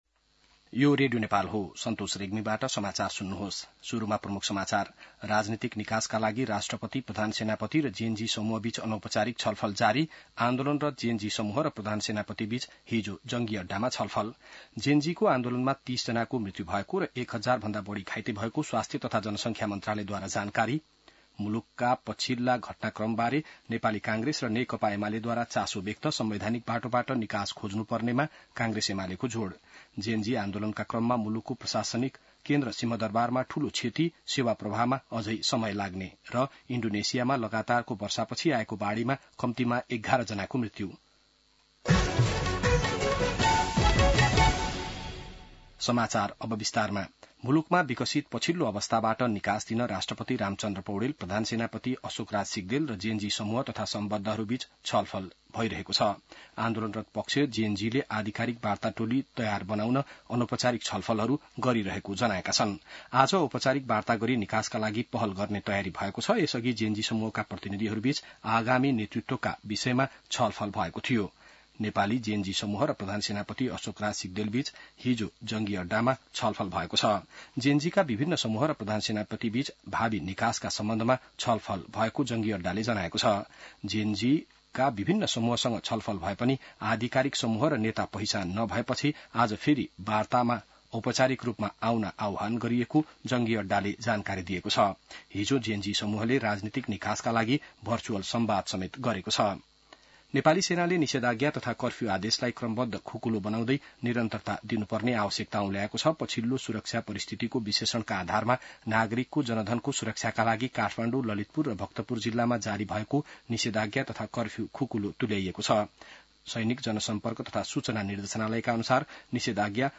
बिहान ९ बजेको नेपाली समाचार : २६ भदौ , २०८२